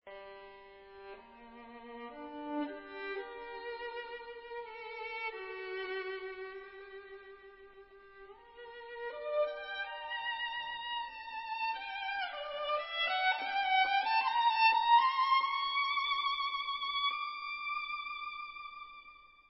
sledovat novinky v kategorii Vážná hudba